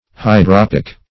Search Result for " hydropic" : The Collaborative International Dictionary of English v.0.48: Hydropic \Hy*drop"ic\, Hydropical \Hy*drop"ic*al\, a. [L. hydropicus, Gr.